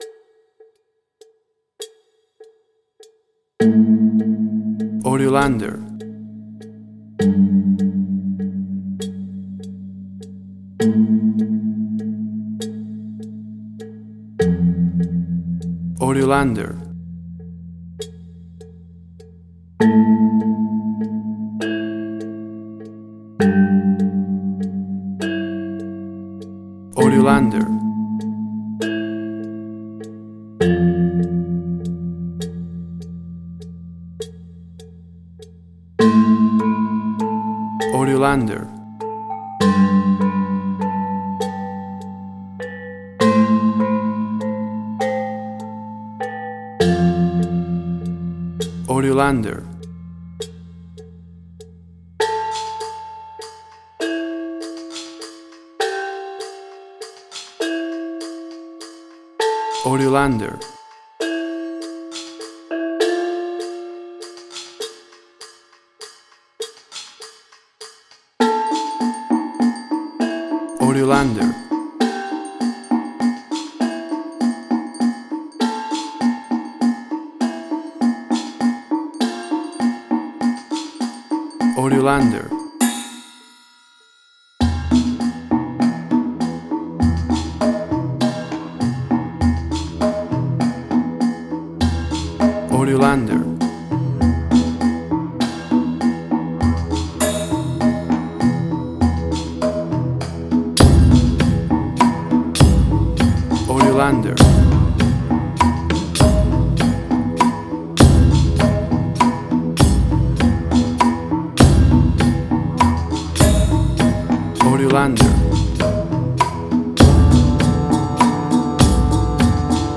Gamelan Ethnic instrumental
Tempo (BPM): 100